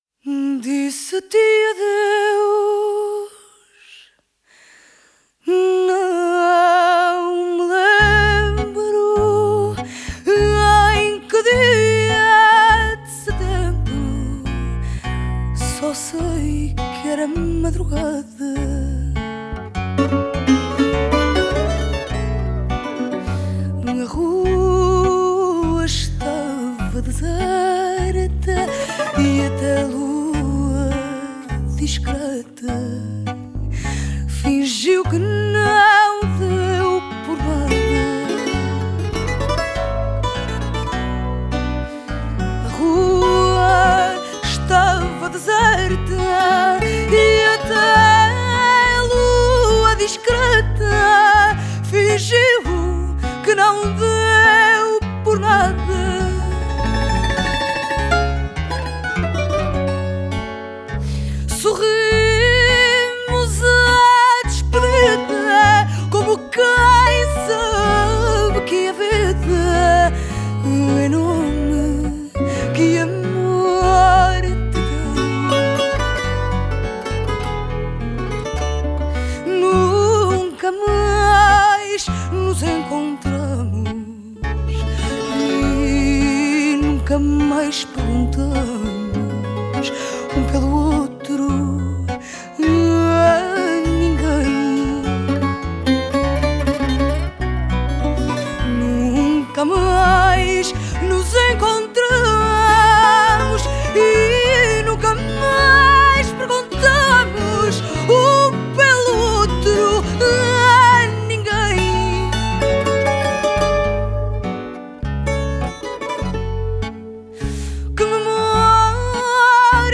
Genre: Fado, Folk, Portuguese music